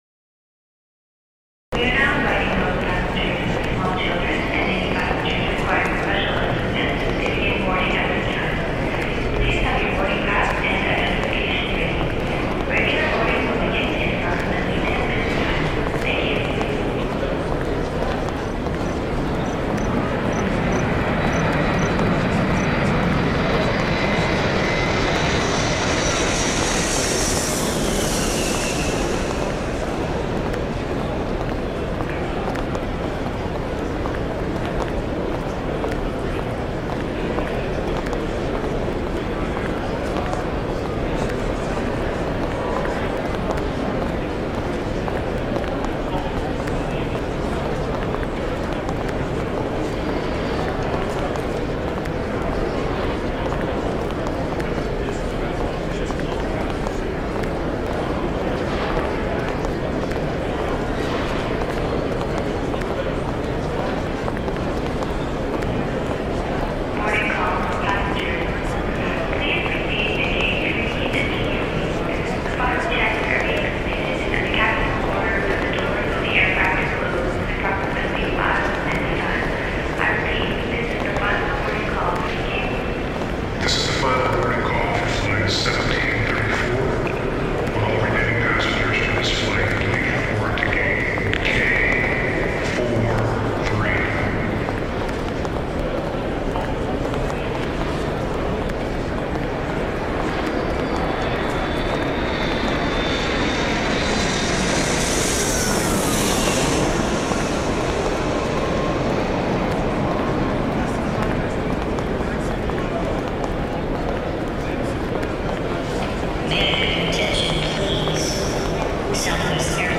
Звуки аэропорта, атмосфера
7. Международный аэропорт мира